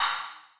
Closed Hats
DrummaCHH.wav